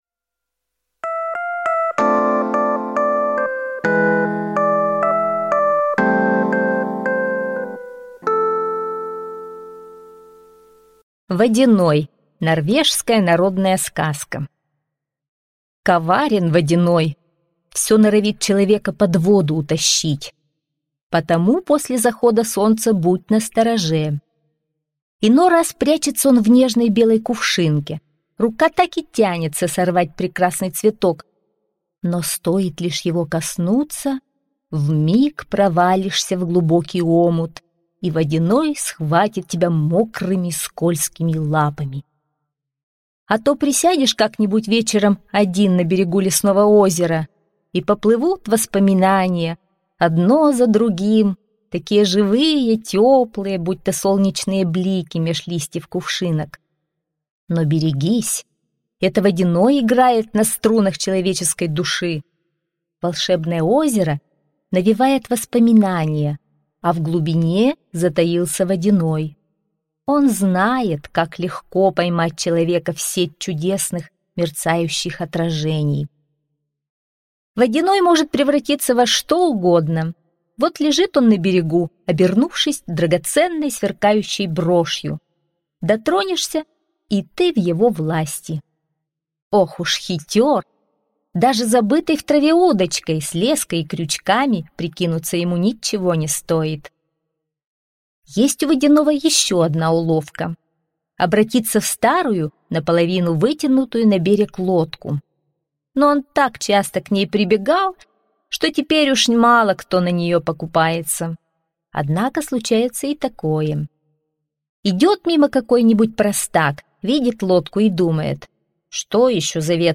Водяной - норвежская аудиосказка - слушать онлайн